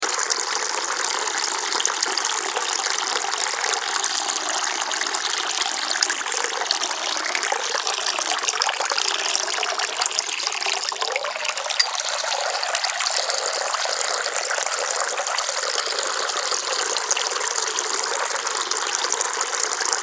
Download Water Streaming sound effect for free.
Water Streaming